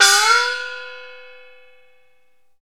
Index of /90_sSampleCDs/Roland LCDP03 Orchestral Perc/CYM_Gongs/CYM_Gongs Dry
CYM BENDGO06.wav